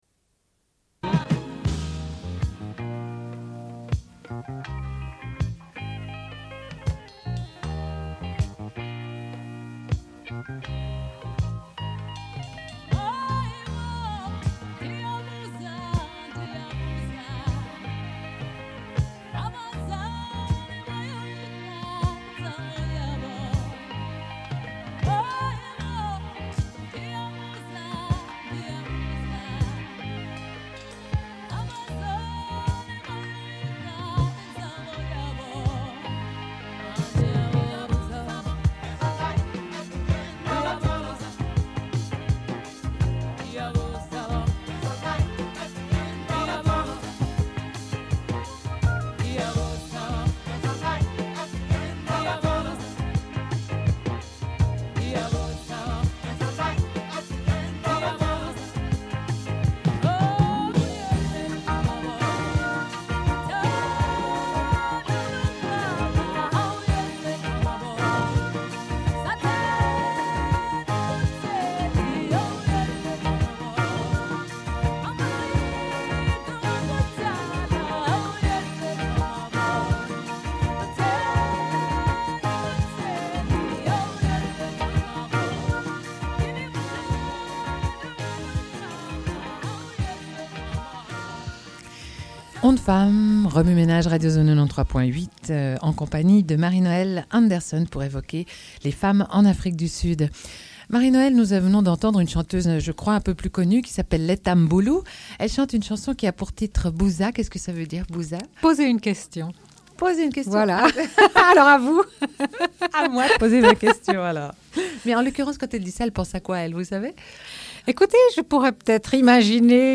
Une cassette audio, face B29:10